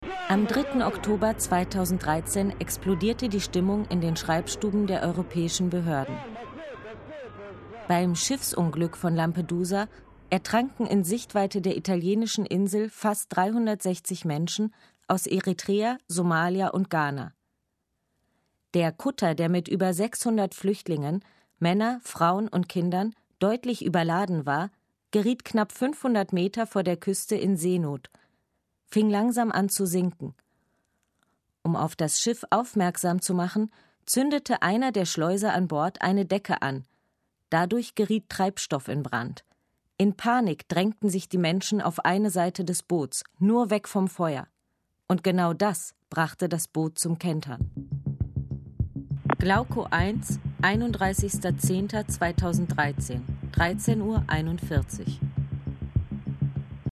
Kulturradio RBB Feature
Rolle: Sprecher